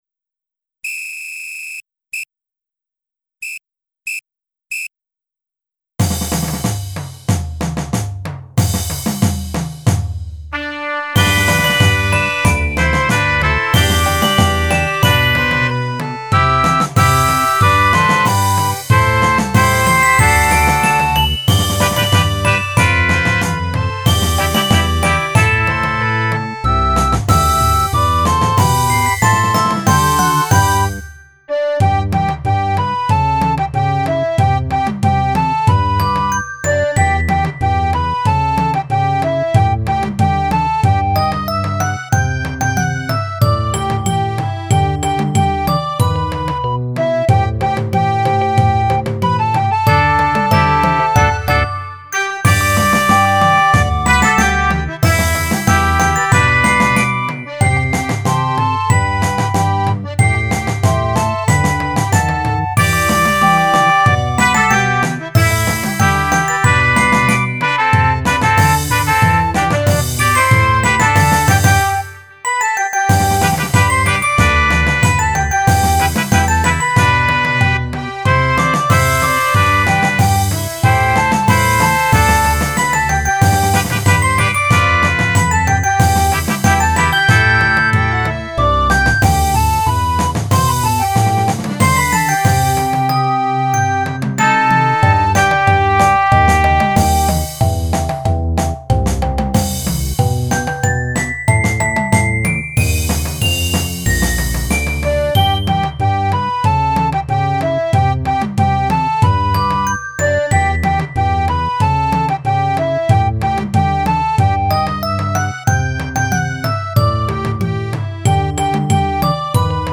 鼓笛アレンジ